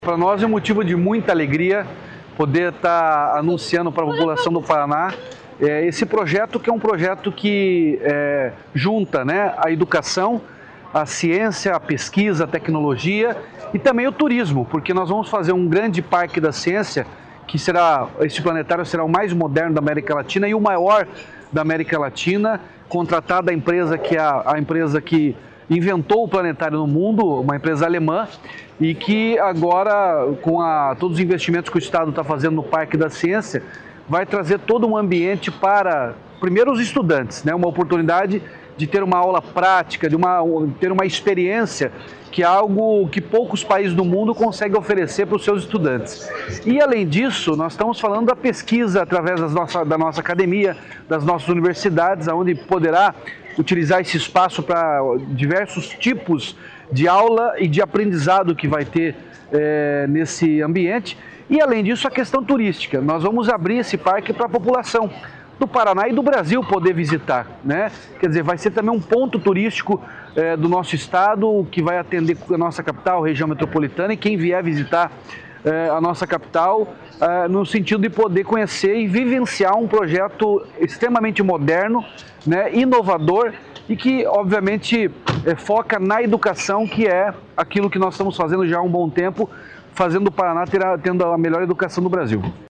Sonora do governador Ratinho Junior sobre a assinatura da parceria com empresa alemã Carl Zeiss